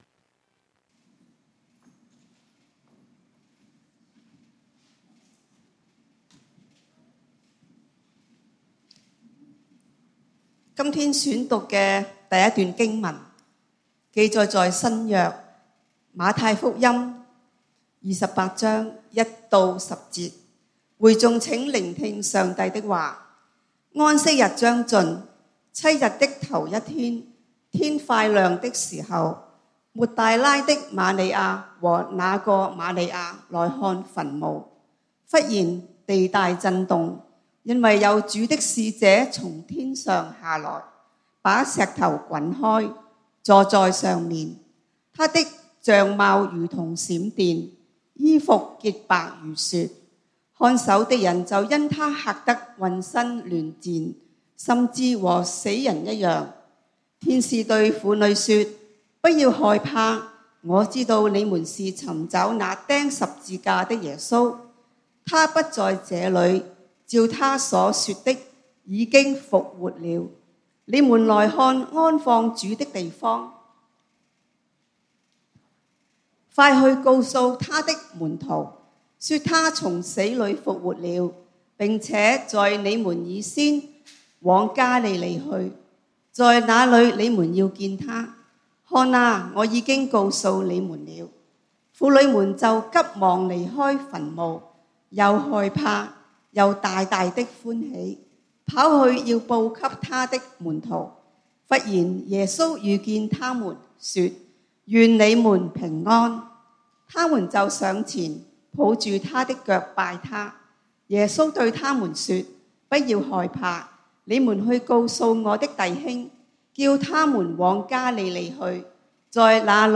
復活節崇拜：絕處逢生 (經文：馬太福音28:1-10，出埃及記14:10-15:1) | External Website | External Website